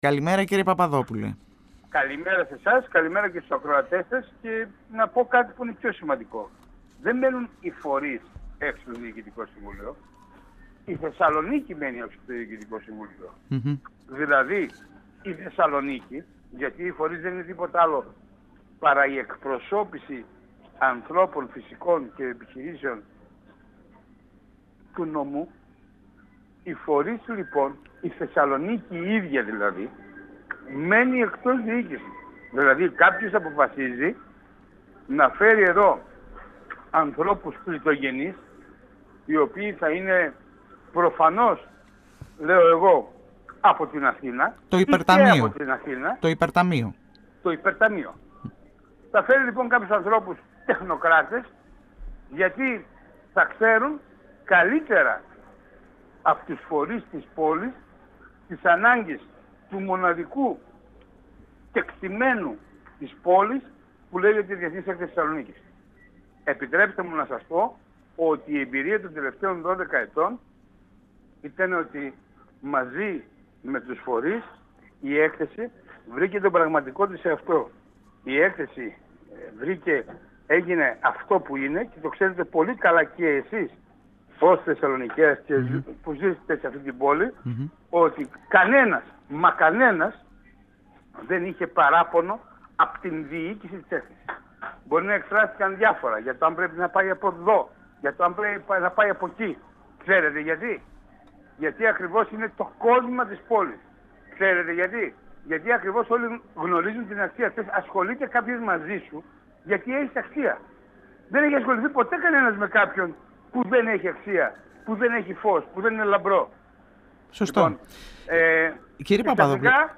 μιλώντας στην εκπομπή   «Εδώ και Τώρα»  του 102FM της ΕΡΤ3.